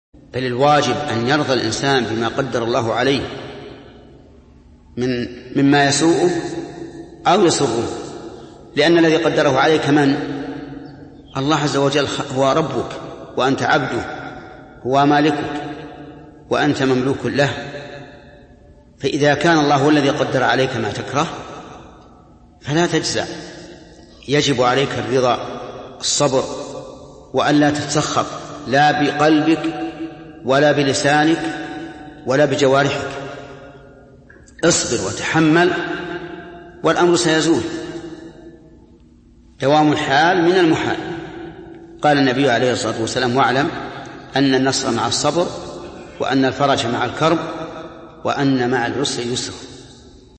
من مواعظ أهل العلم